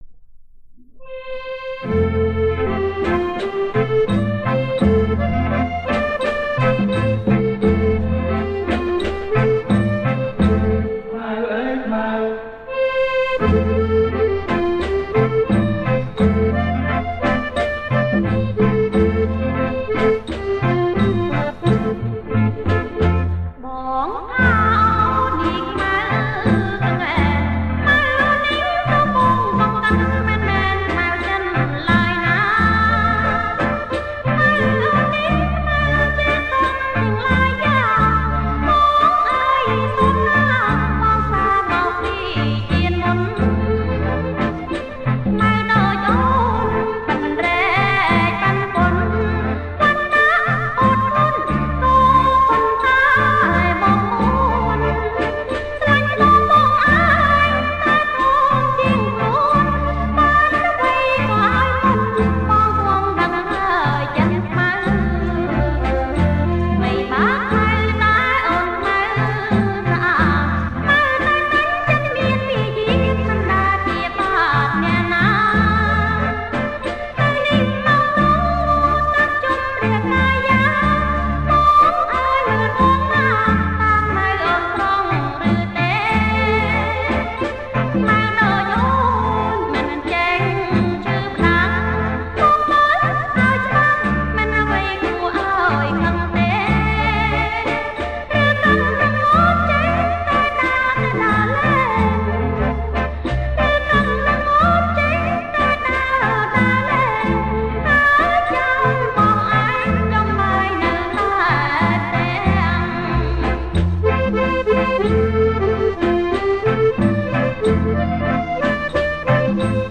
• ប្រគំជាចង្វាក់ Slow Kbach